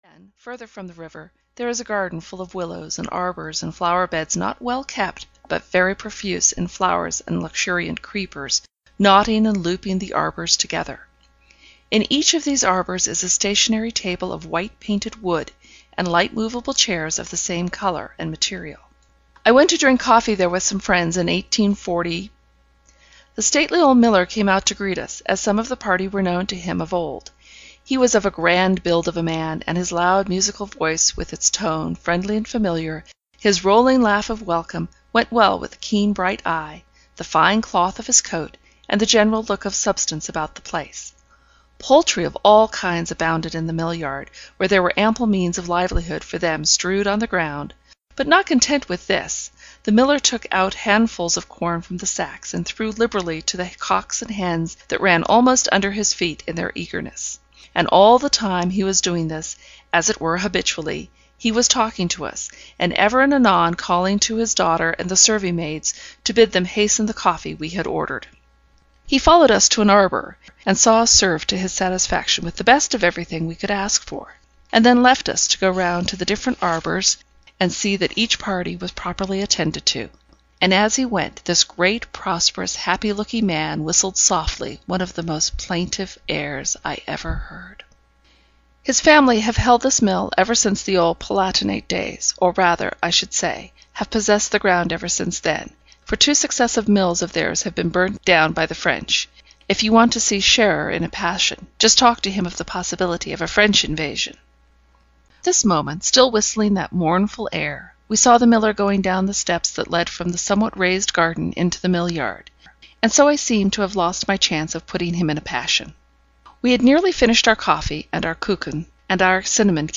The Grey Woman (EN) audiokniha
Ukázka z knihy